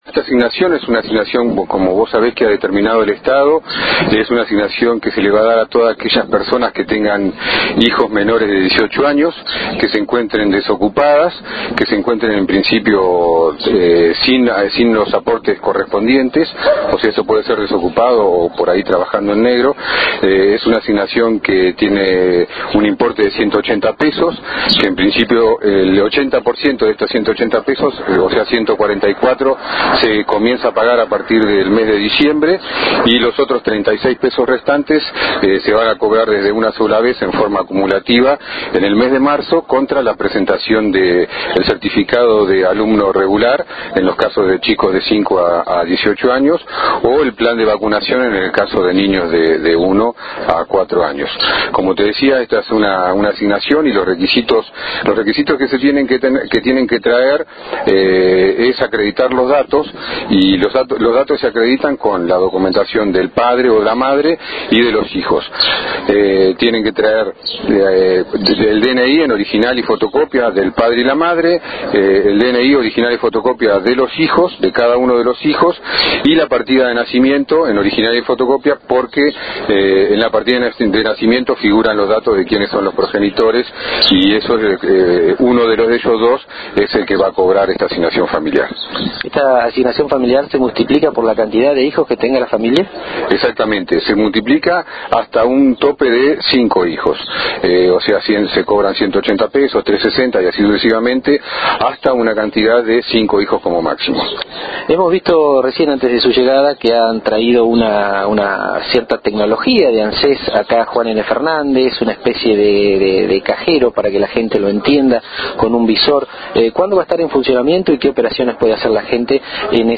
En entrevista exclusiva para JNFNet dijo también que en pocos días comenzará a funcionar una unidad de gestión, que ya está en la localidad, donde se podrán realizar trámites de ese organismo.